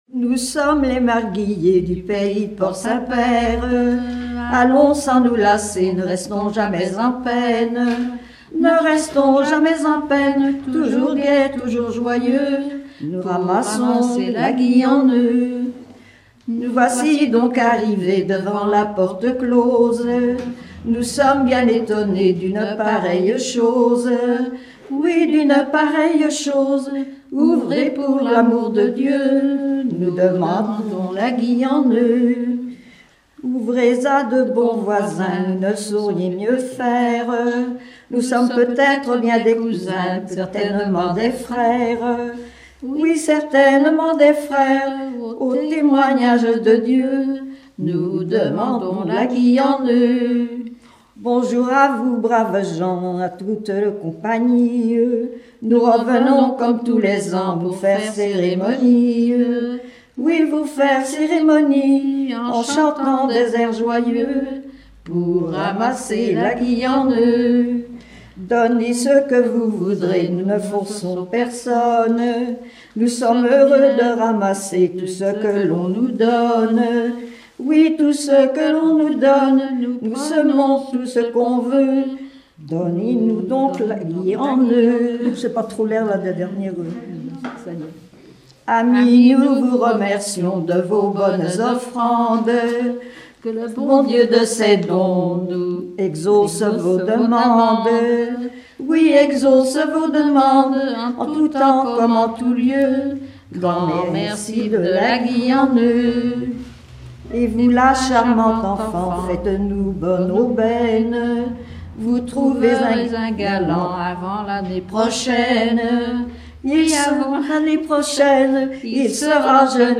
circonstance : quête calendaire
Pièce musicale éditée